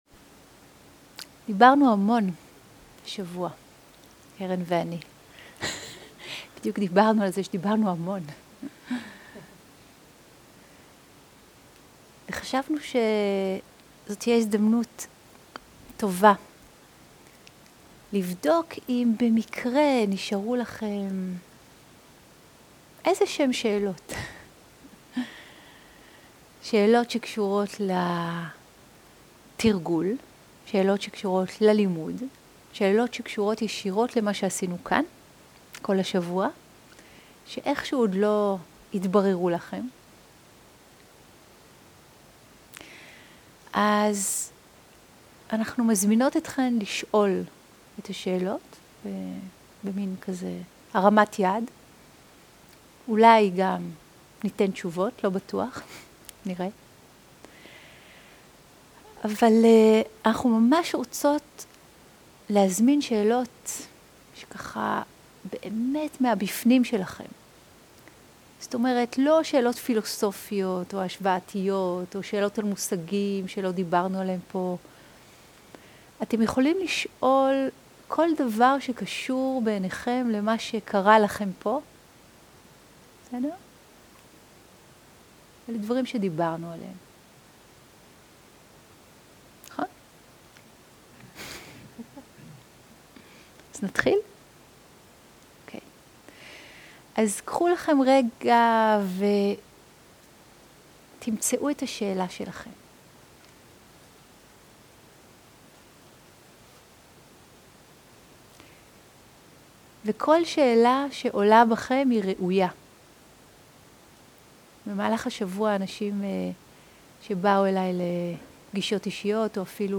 שאלות ותשובות